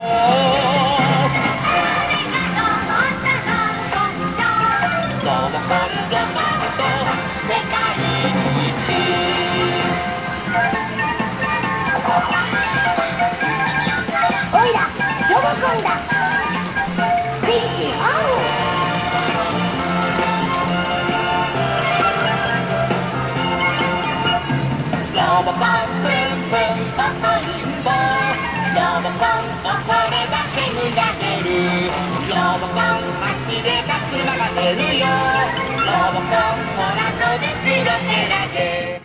pachinko6.mp3